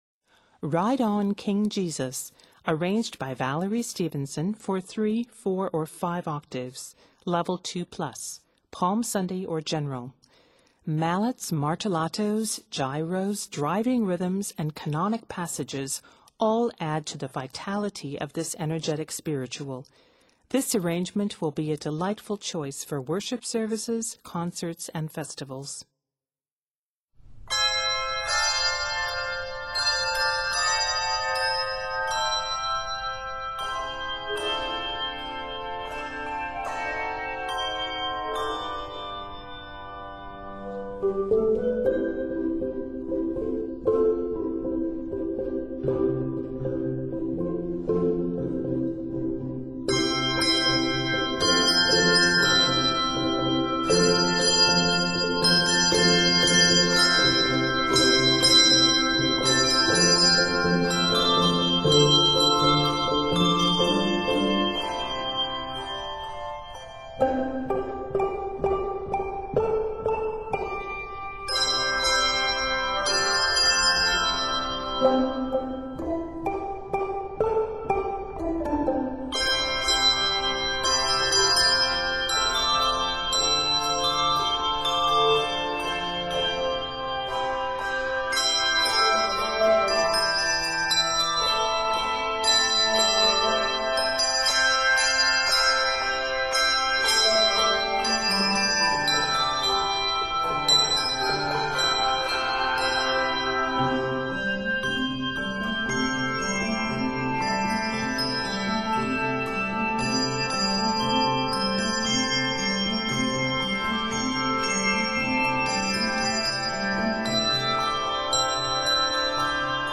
Arranged in C Major, this piece is 65 measures.